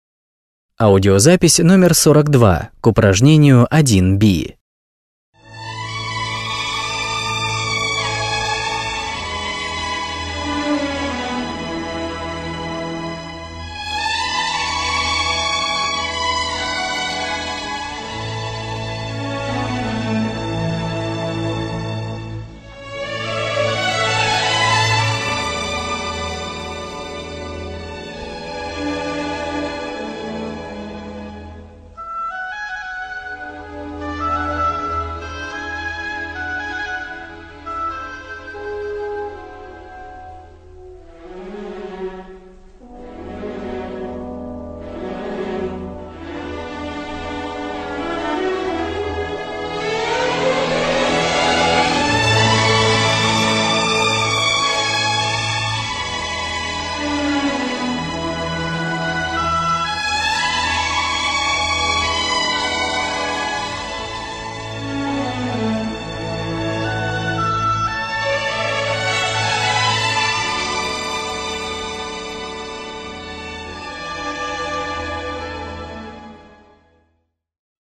When I listen to this music, it sounds sad and sweet.